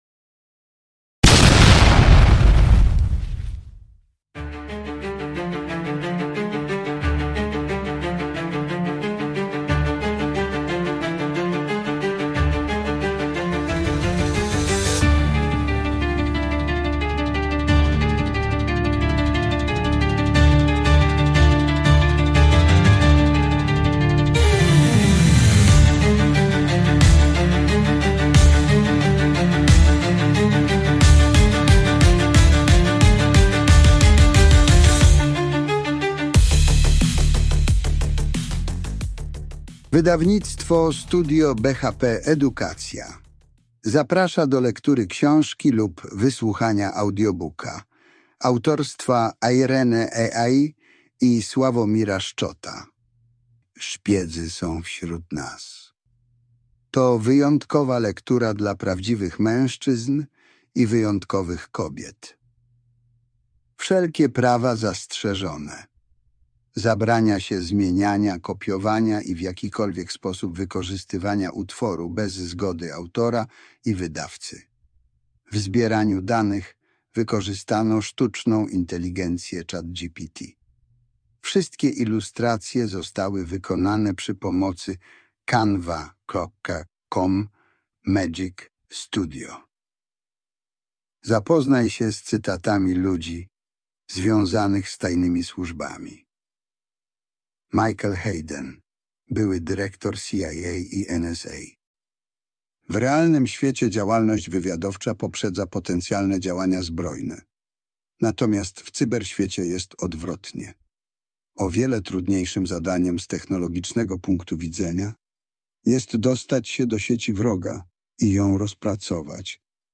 Szpiedzy są wśród nas – Audiobook